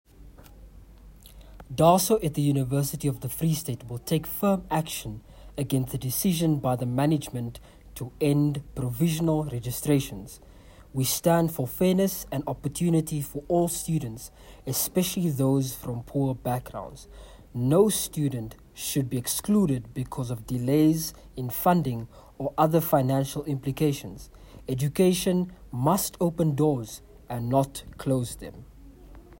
English soundbite